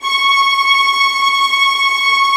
VIOLINT .2-L.wav